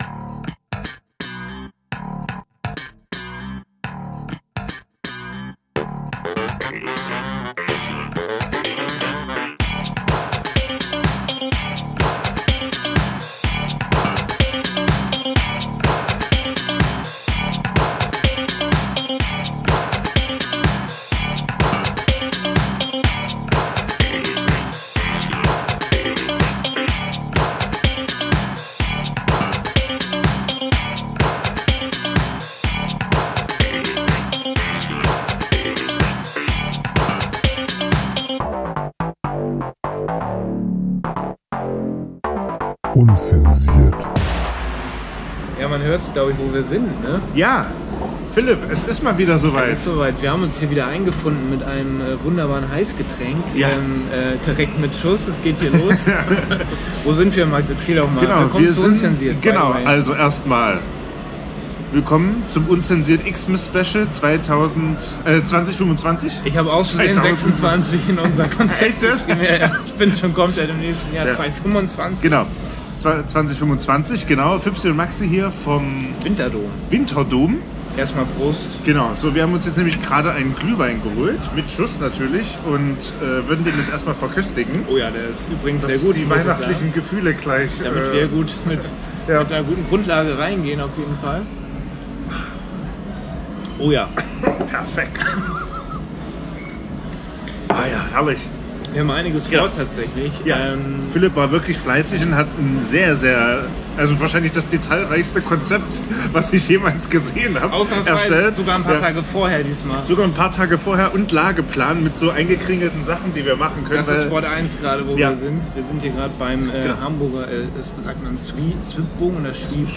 live on tape auf dem Hamburger Winterdom.